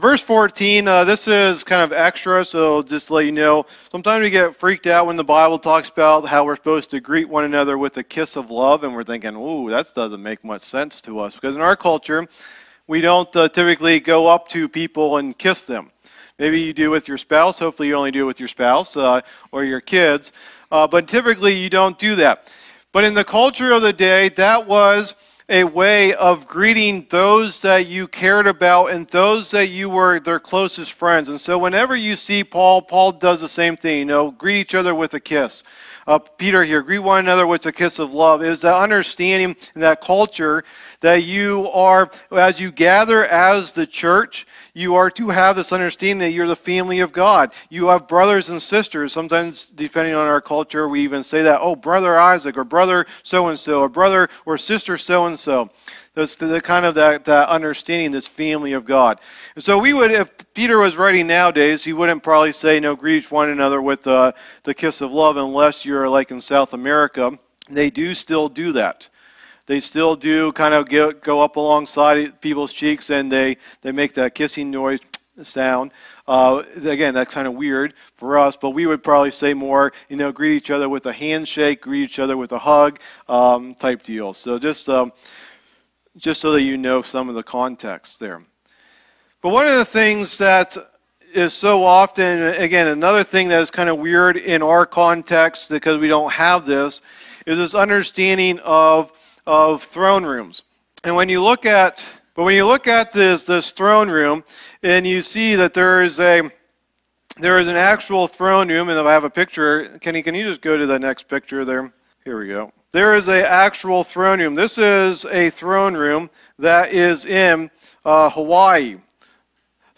Sunday Morning Teachings | Bedford Alliance Church